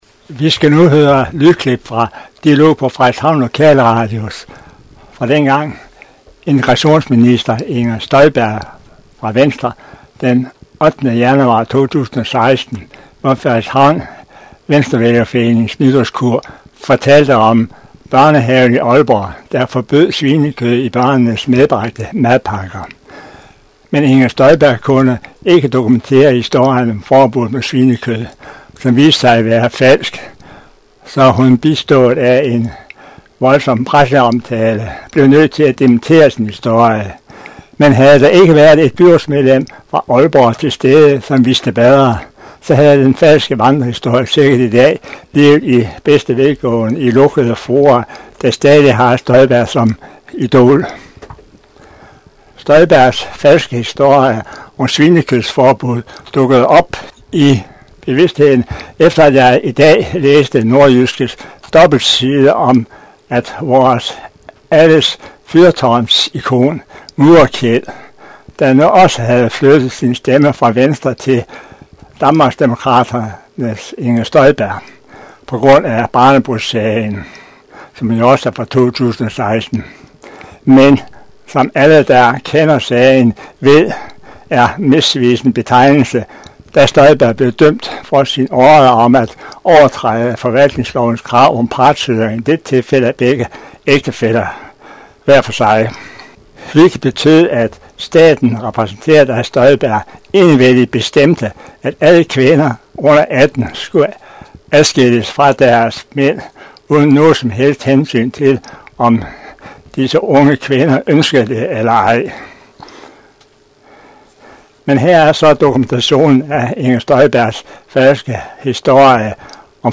Nugældende link til den oprindelige optagelse af Inger Støjberg i Frederikshavn Venstrevælgerforening: